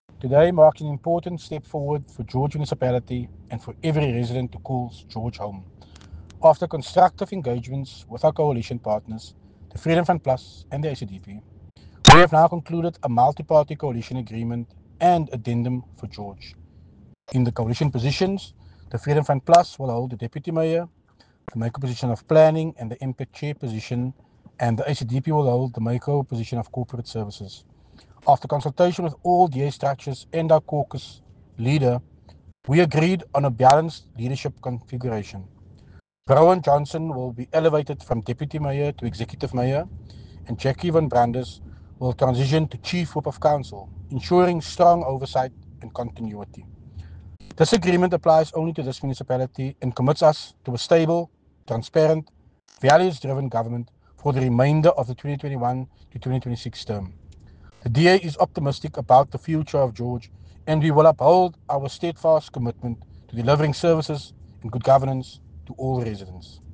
soundbite by Tertuis Simmers